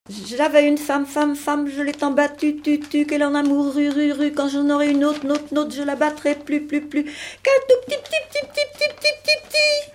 Saint-Mars-de-Coutais
Genre brève
Pièce musicale inédite